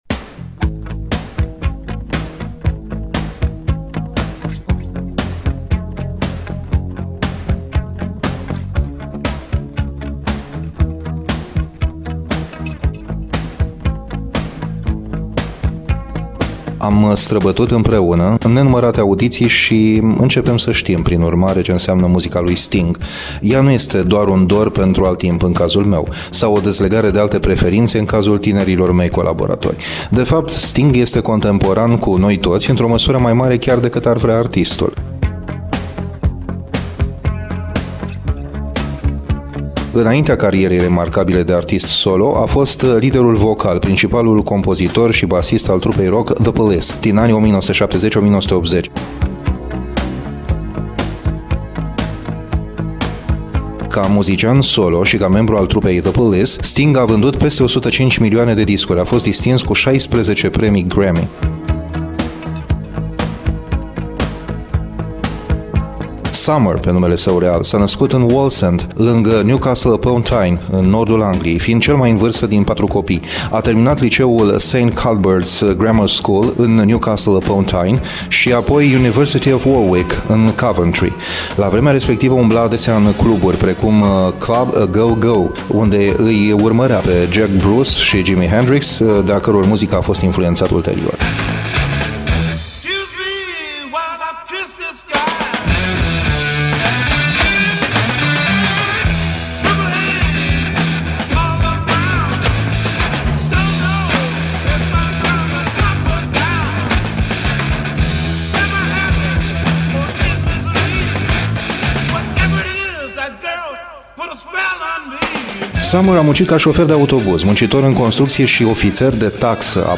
feature